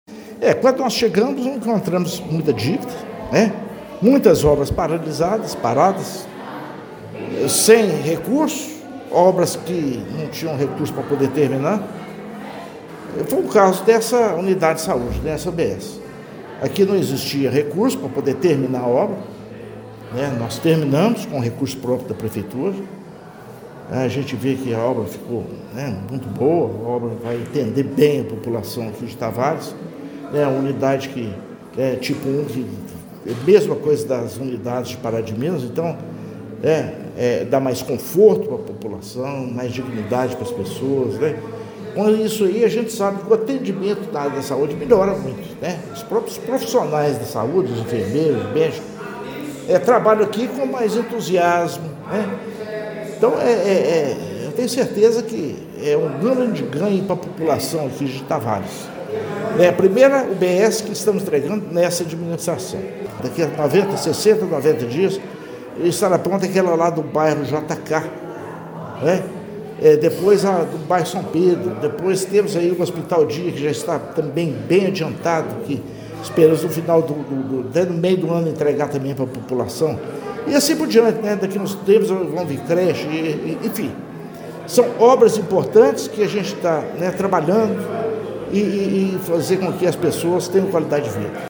O prefeito Inácio Franco celebrou a entrega da unidade e reforçou que outras obras importantes estão em andamento no município, como as UBSs dos bairros São Pedro e JK, além do Hospital Municipal Dia, ampliando o acesso à saúde e a qualidade de vida da população: